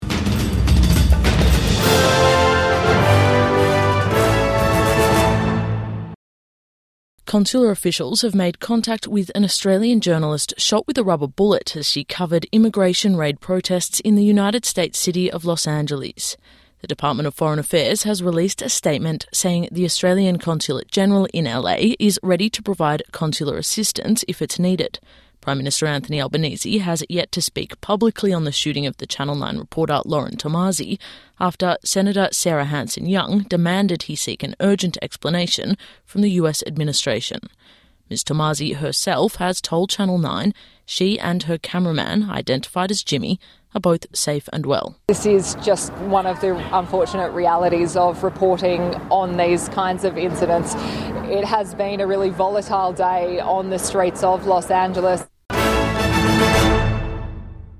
A journalist speaks after being shot with a rubber bullet during immigration protests